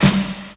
• Classic Punk Snare Sample G Key 704.wav
Royality free steel snare drum sample tuned to the G note. Loudest frequency: 1730Hz
classic-punk-snare-sample-g-key-704-Bm0.wav